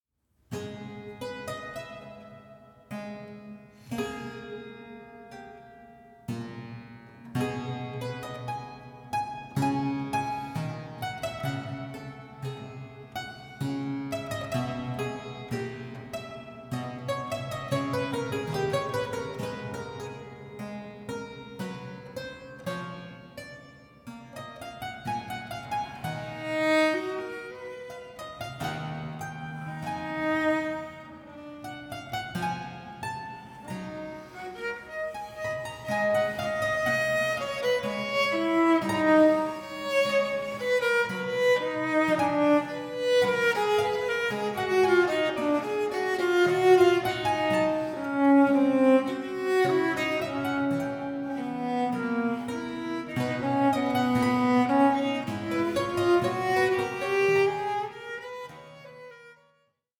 Largo 5:50